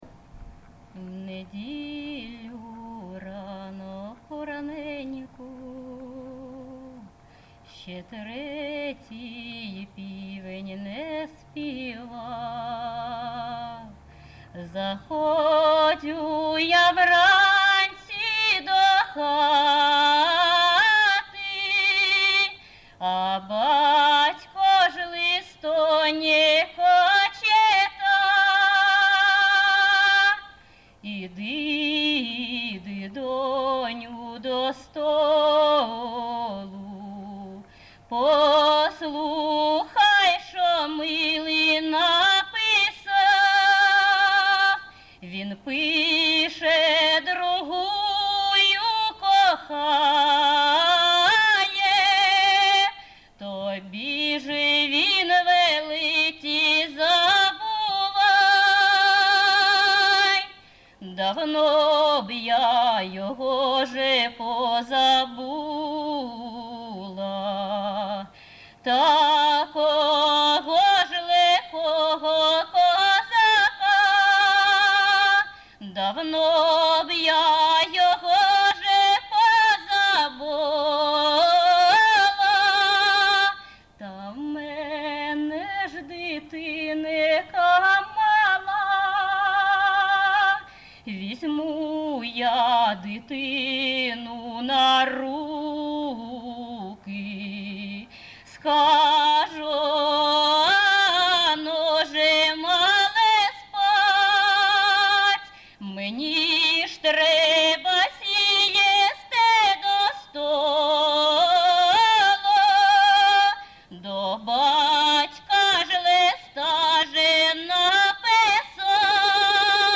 ЖанрПісні з особистого та родинного життя, Балади, Сучасні пісні та новотвори
Місце записус. Гусарівка, Барвінківський район, Харківська обл., Україна, Слобожанщина
Виконавиця співає не в традиційній, а в сценічній манері